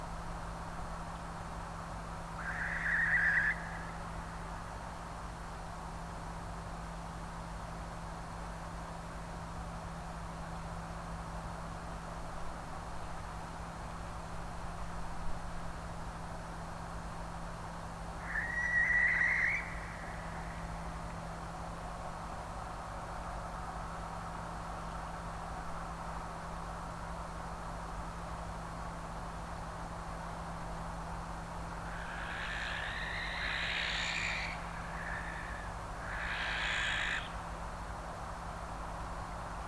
le chant de l' effrraie